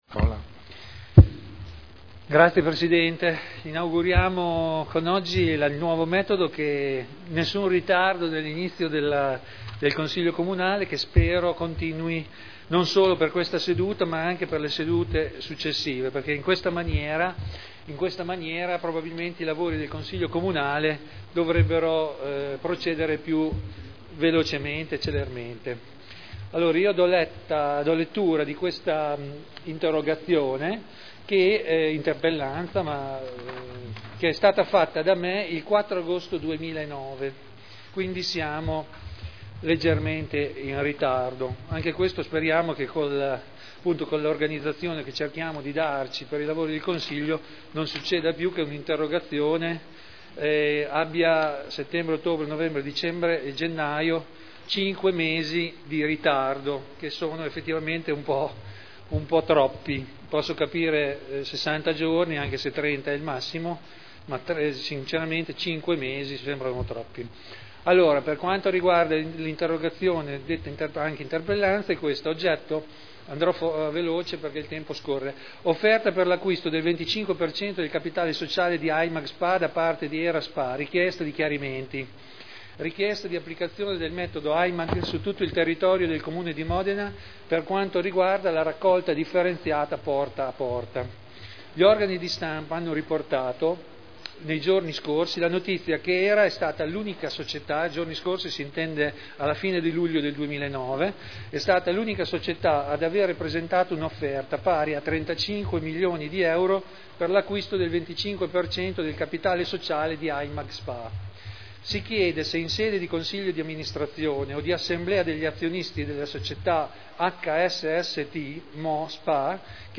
Seduta del 01/02/2010.